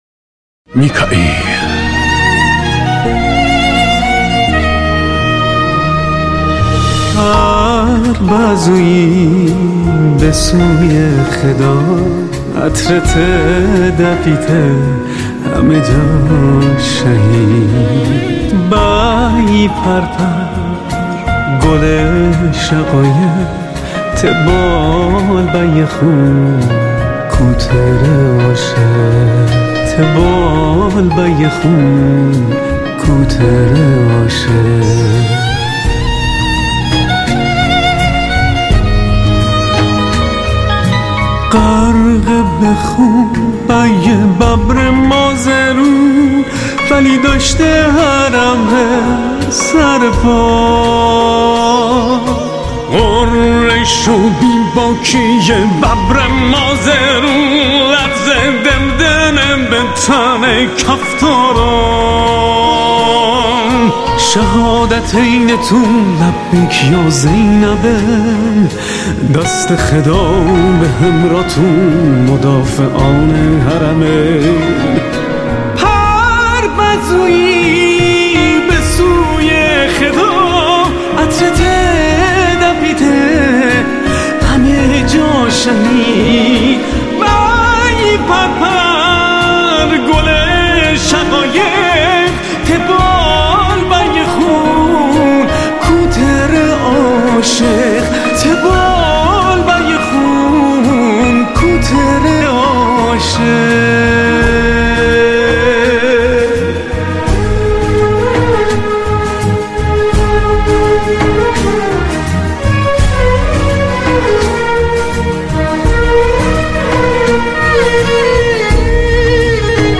یک قطعه شعر+یک ترانه محلی تولید هنرمندان استان که به شهدای مازندرانی مدافع حرم تقدیم شده است.
ترانه ای مازندرانی